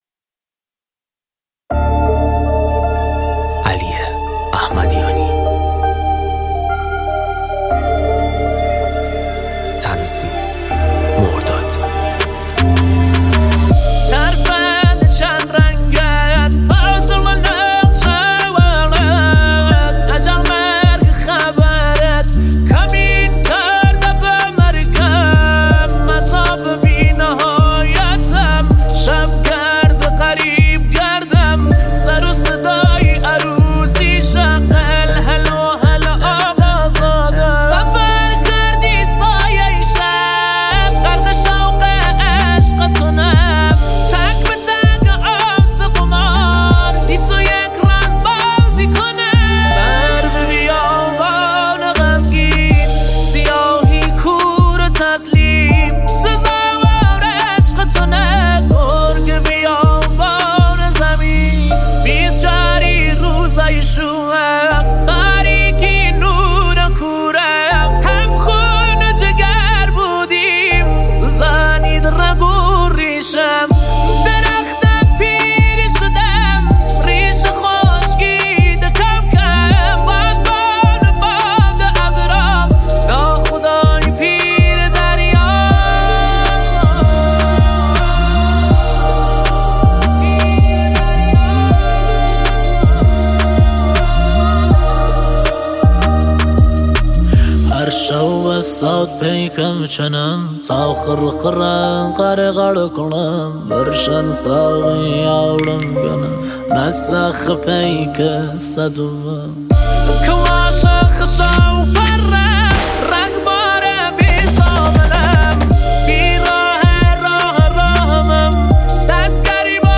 یه آهنگ شاد و محلی
با حال‌و‌هوای عروسی‌های کردی و لُری.